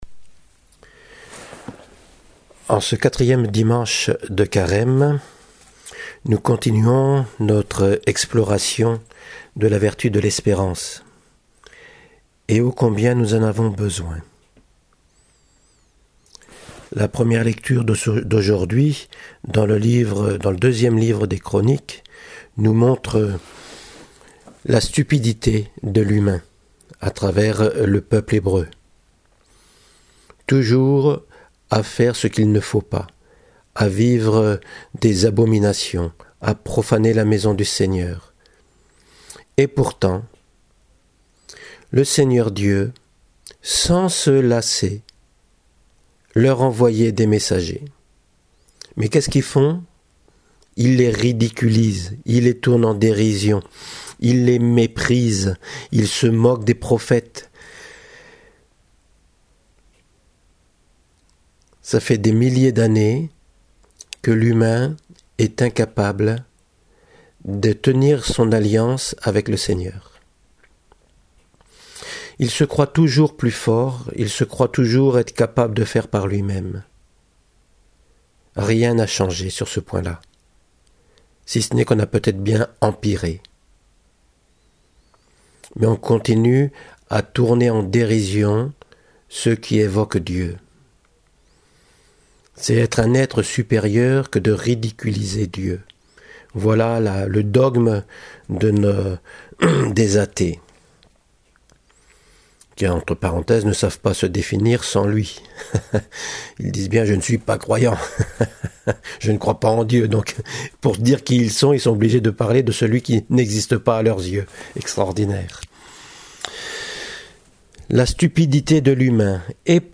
homélie du dimanche avancer vers le mystère de l’origine de la Vie